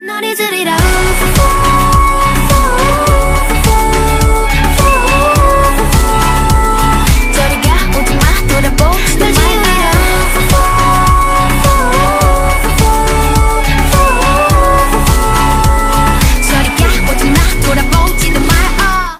ритмичные
громкие
женский вокал
K-Pop